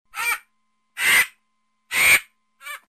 Утка
Категория: Голоса животных